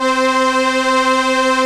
Index of /90_sSampleCDs/Keyboards of The 60's and 70's - CD1/STR_ARP Strings/STR_ARP Solina